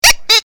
clock07.ogg